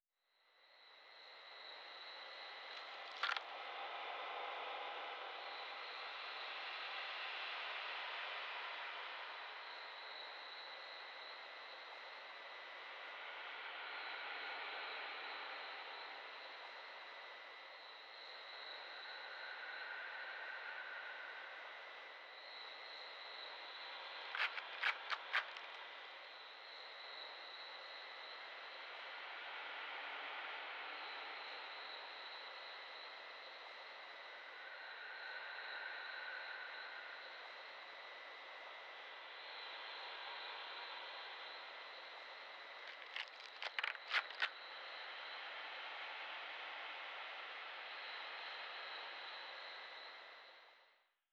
环境音
03_一楼院子.wav